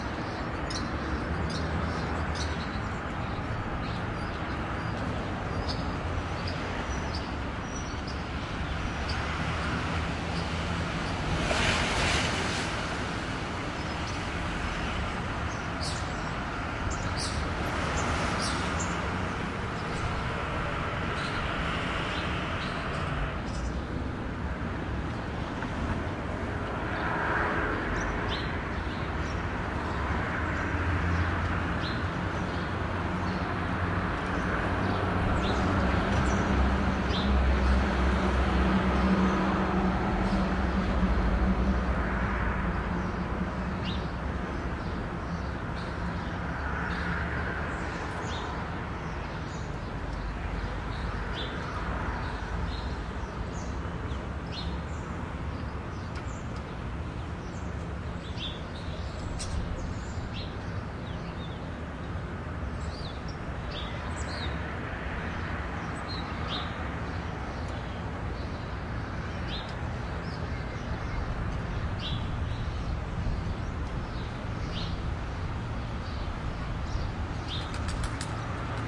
蒙特利尔 " 孩子们喊着玩小巷角落里的回声与附近经过的街道交通魁北克凡尔登，加拿大蒙特利尔
描述：孩子们喊着胡同角回声与附近路过的街道交通魁北克凡尔登，蒙特利尔，Canada.flac